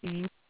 random background noise hmm transformation
Tech. description: 8khz, 16 bit mono adpcm